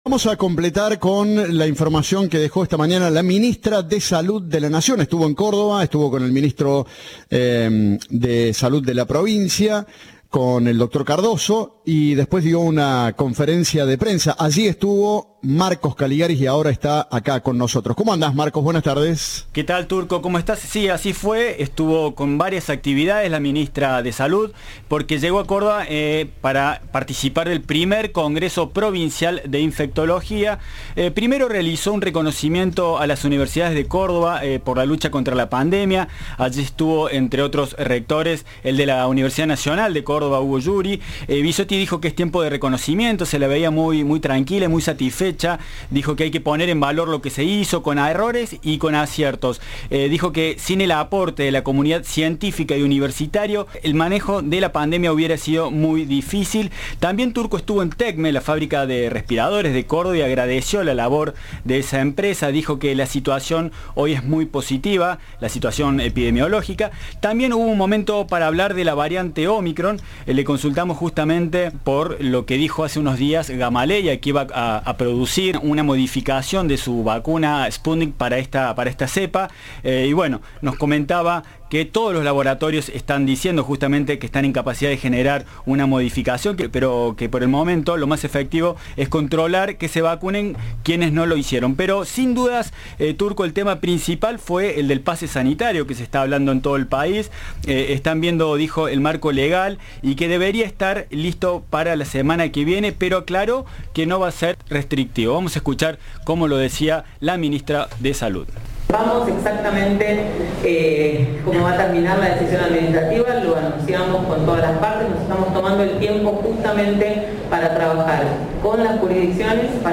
La ministra de Salud de la Nación, Carla Vizzotti, estuvo presente este viernes en el 1º Congreso de Infectología realizado en la ciudad de Córdoba.
En contacto con la prensa, la funcionaria manifestó que están avanzando en el marco legal del pase sanitario, el cual podría estar listo la semana próximo.